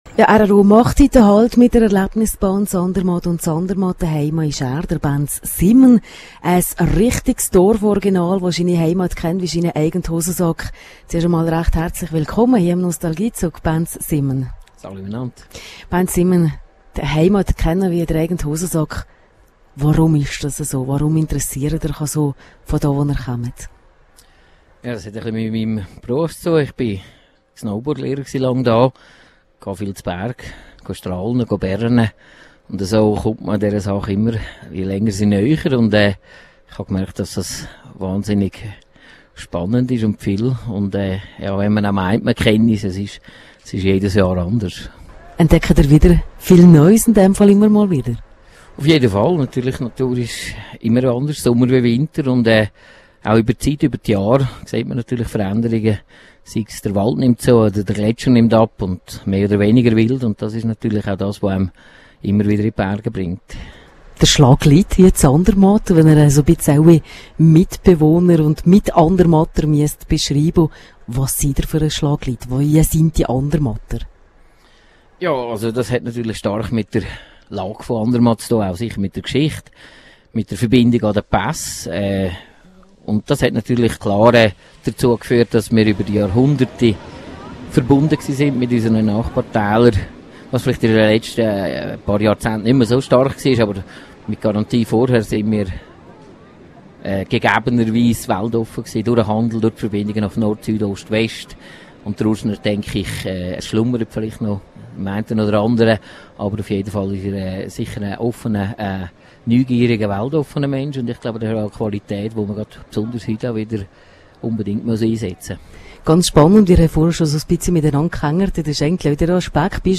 Er schildert die Geschichte dieses Tales spannend in seinem sympathischen Urschener Dialekt.
Hier noch ein Interview